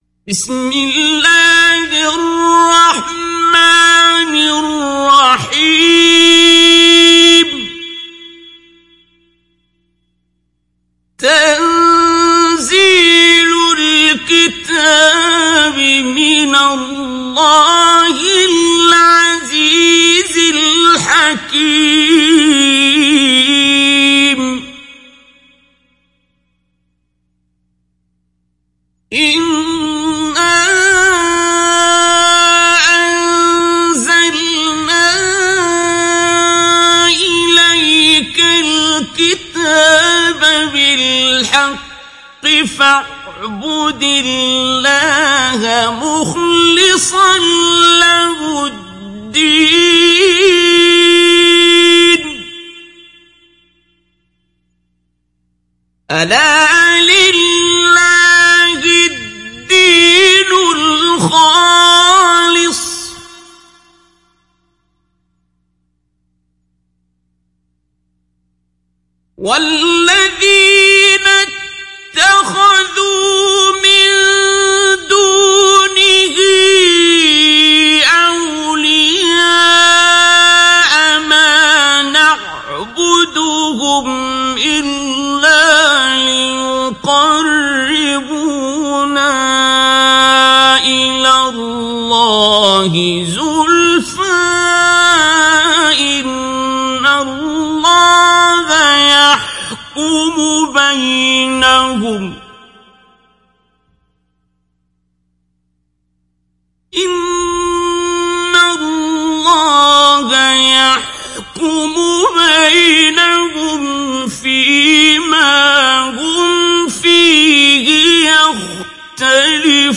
Zümer Suresi İndir mp3 Abdul Basit Abd Alsamad Mujawwad Riwayat Hafs an Asim, Kurani indirin ve mp3 tam doğrudan bağlantılar dinle
İndir Zümer Suresi Abdul Basit Abd Alsamad Mujawwad